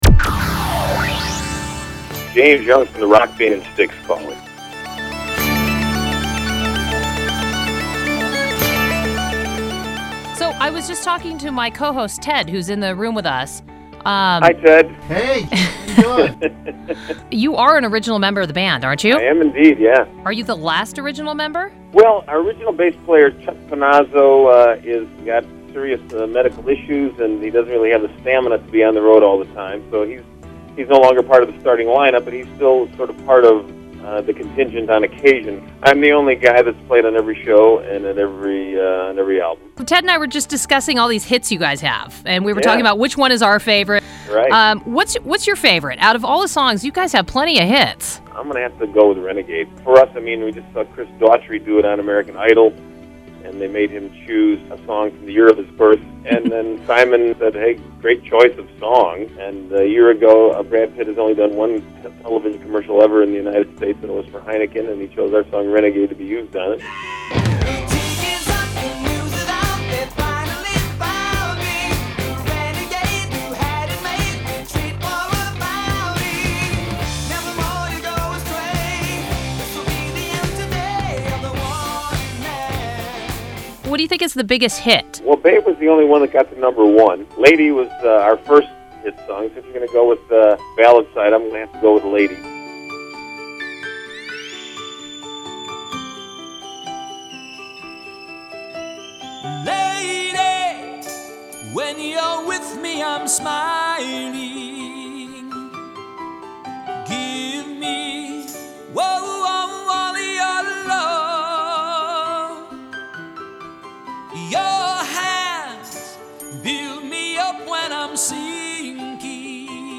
It has music clips and some radio-sounding bells and whistles, so it could be aired, but I don’t think it will because it’s too long.
After some creative editing I made it sound like he started with a thought and ended that thought in a concise manner.